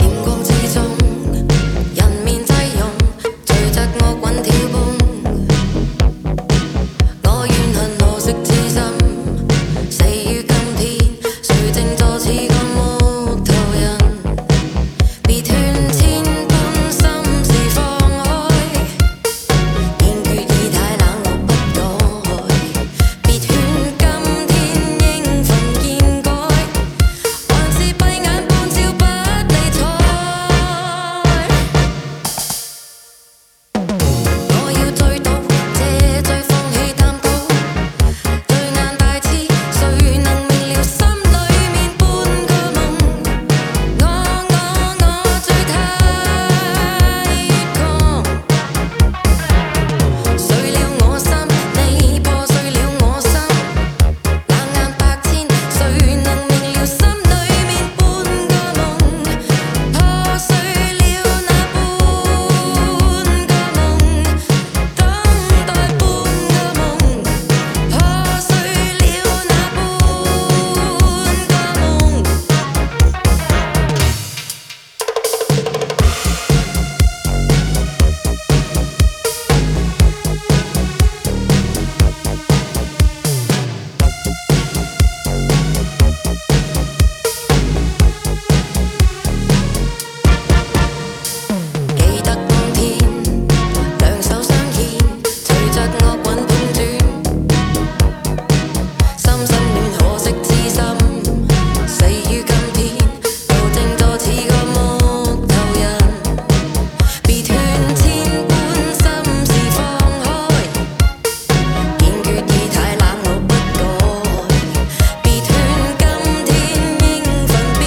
エレクトリックな80sディスコ路線のオリジナルと比べ、レゲエの要素やメロウなアレンジを際立たせた仕上がり。